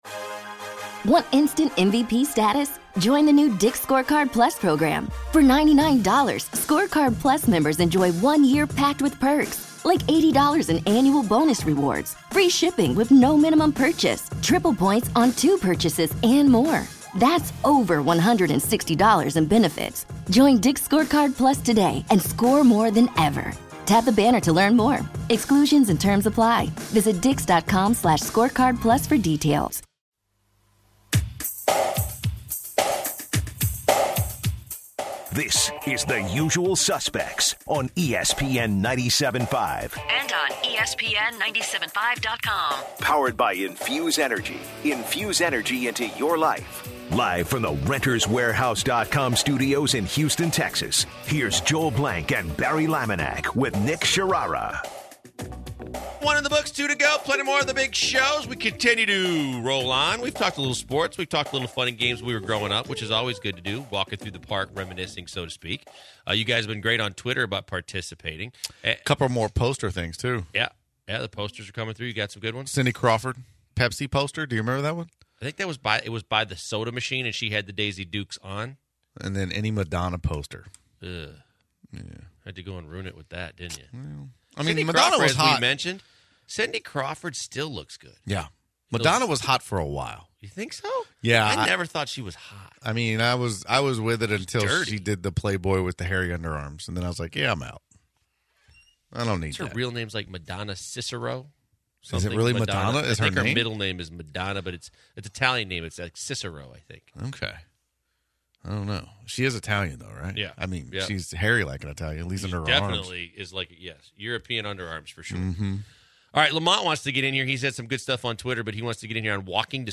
Throughout the show, the guys take calls from listeners reminiscing about their younger days with some especially hilarious band stories. At the end of the hour hilarity ensues as Special Guest Paulie Shore joins the guys in the booth.